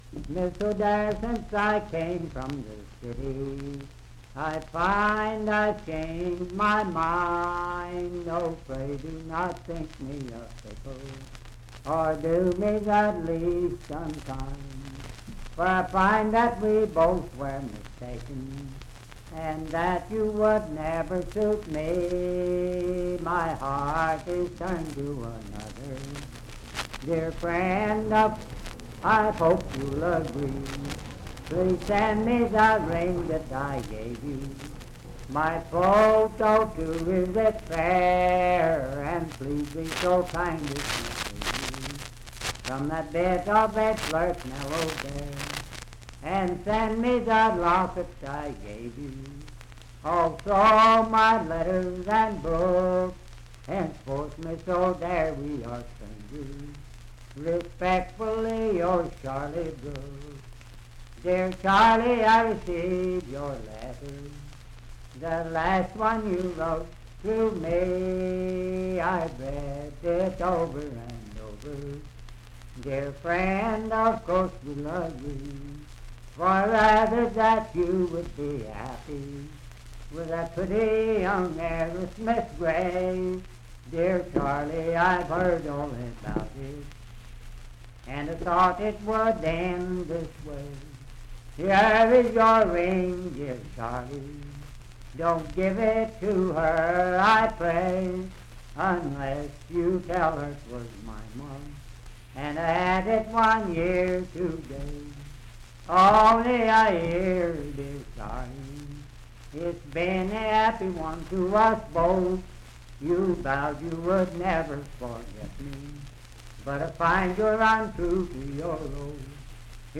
Unaccompanied vocal music
Verse-refrain 11(4).
Voice (sung)
Parkersburg (W. Va.), Wood County (W. Va.)